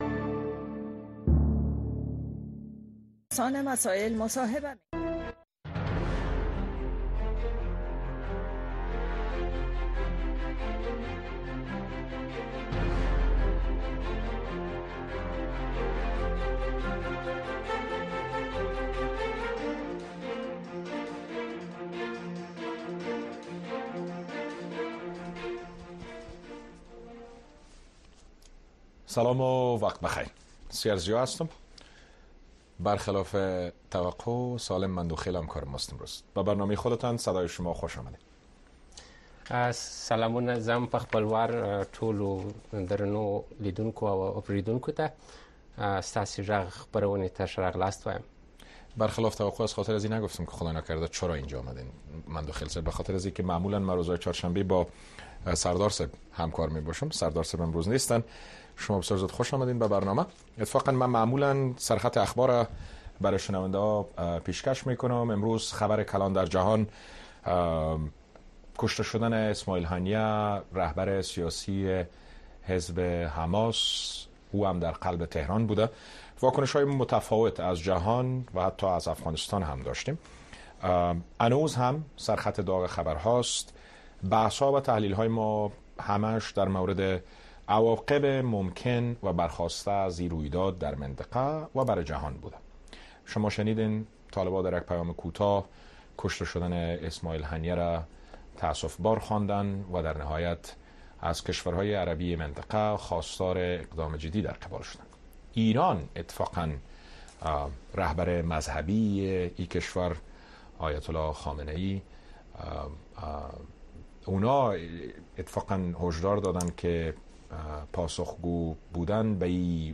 این برنامه به گونۀ زنده از ساعت ۹:۳۰ تا ۱۰:۳۰ شب به وقت افغانستان نشر می‌شود.